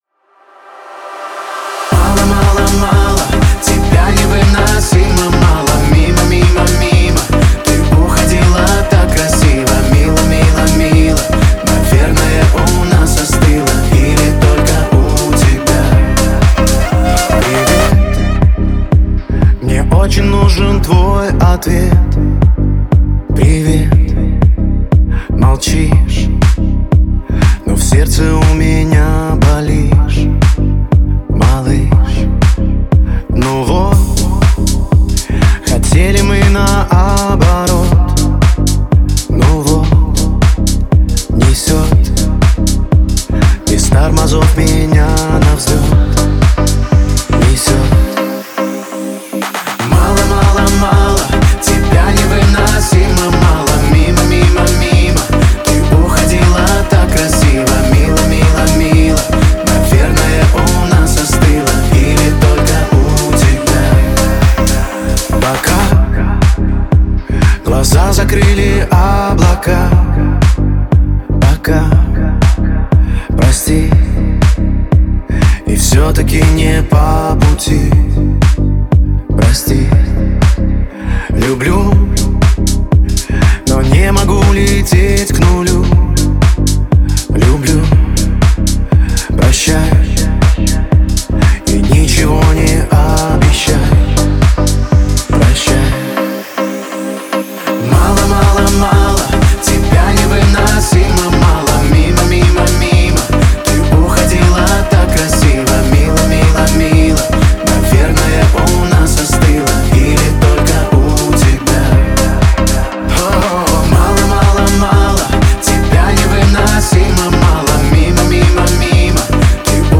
запоминающиеся мелодии и динамичные биты